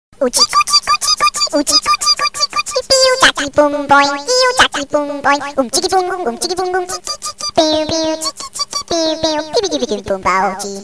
Ребенок SMS